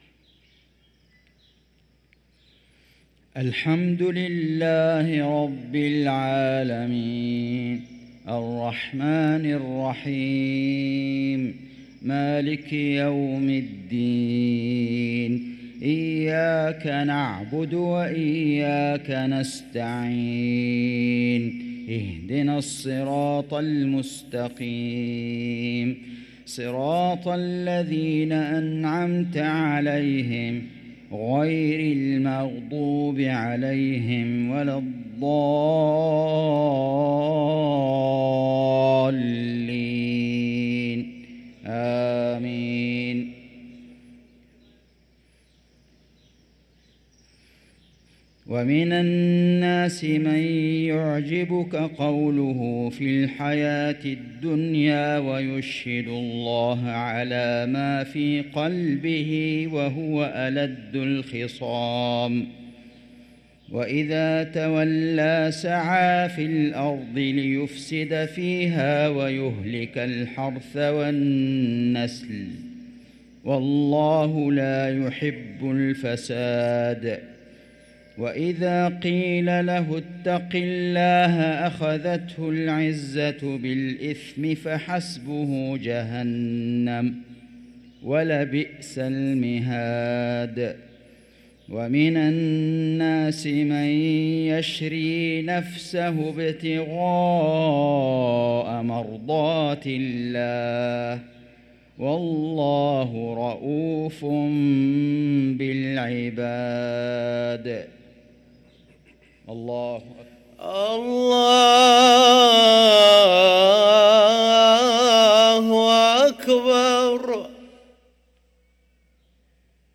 صلاة المغرب للقارئ فيصل غزاوي 23 جمادي الأول 1445 هـ
تِلَاوَات الْحَرَمَيْن .